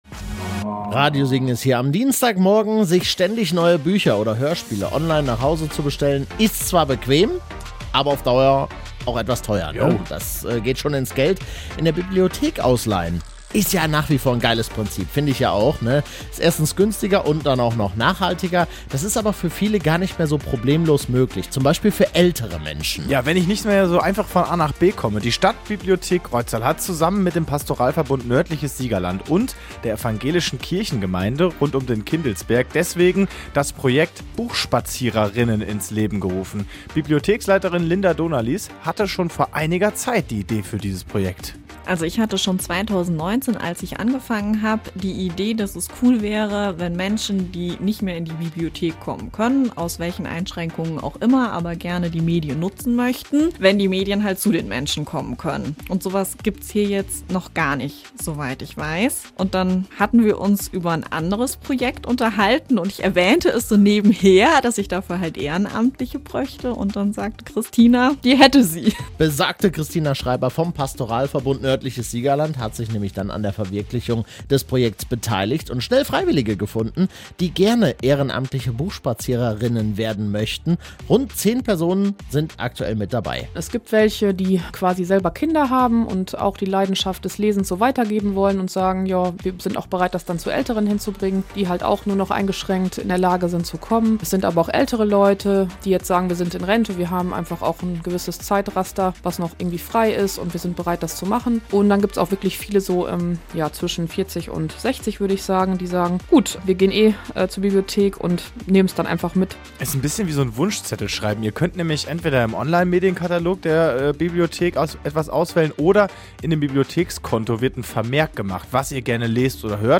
Im Interview erklärt sie, wie die Idee entstanden ist.